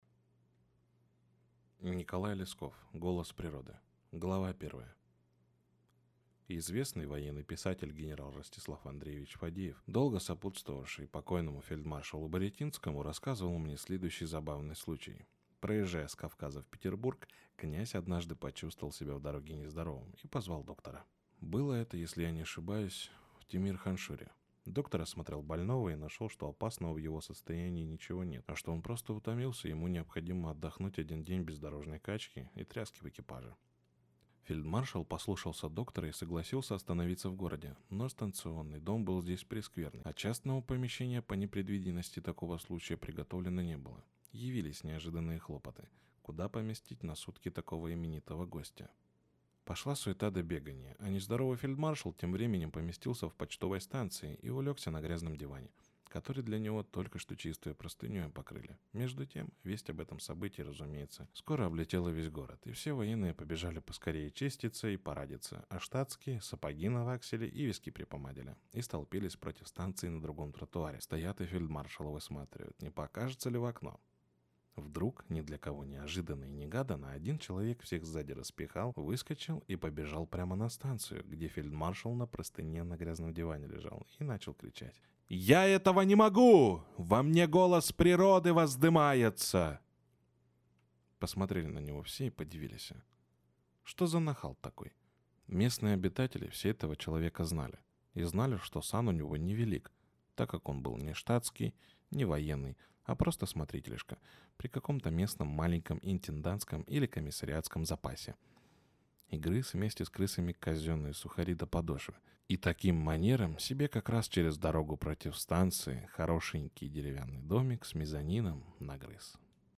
Аудиокнига Голос природы | Библиотека аудиокниг